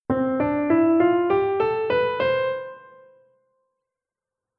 Ein wesentliches Merkmal der Dur-Tonleiter ist der deutlich hörbare Schlusston. Man hat das Gefühl, als würde man am Ende »zu Hause ankommen«.
Bei den Tonleitern spielt man den jeweils 8. Ton mit, so dass der Anfangs- und Endton den gleichen Notennamen haben = also z.B. von c‘ bis c“.
TonleiternCDur.mp3